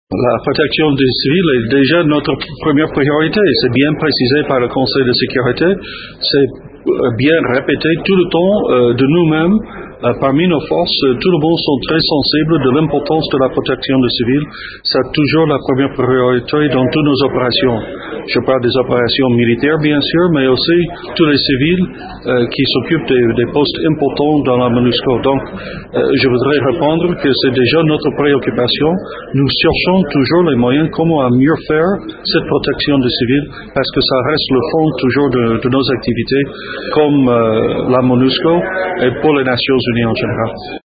Roger Meece explique :